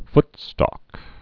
(ftstôk)